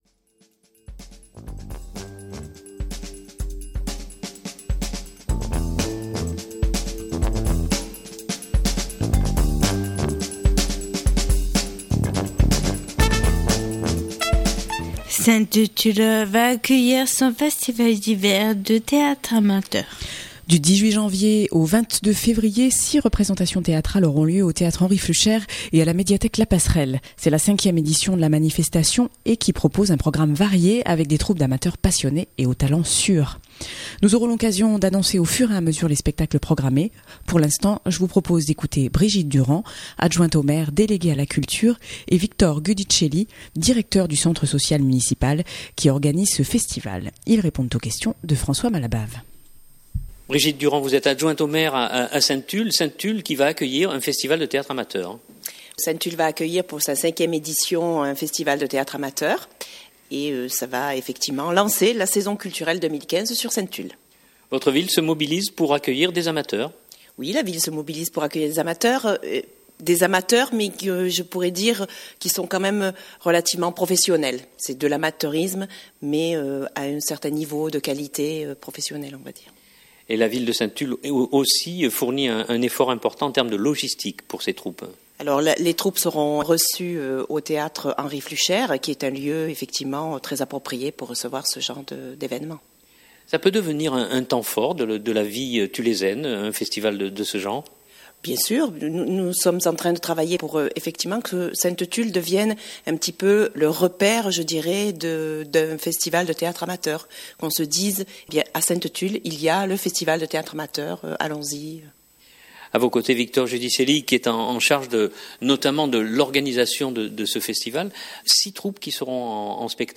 Ils répondent aux questions